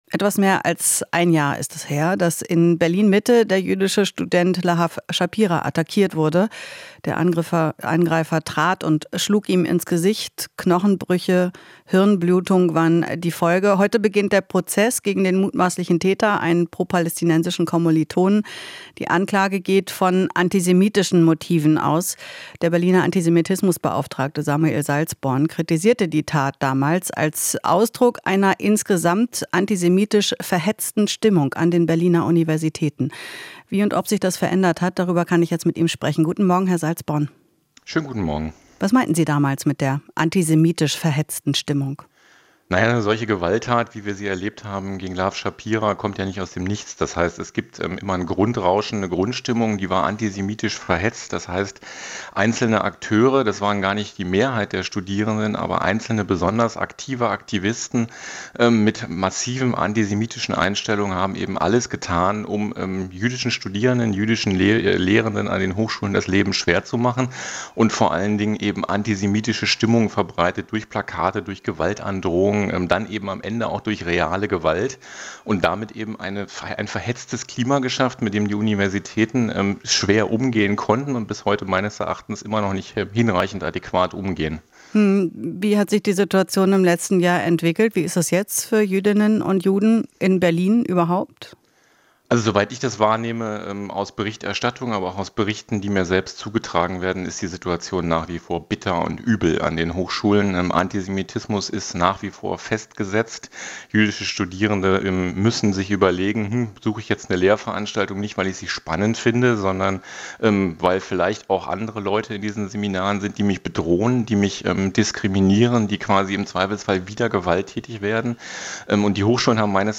Interview - Antisemitismusbeauftragter: Hochschulen haben zu wenig getan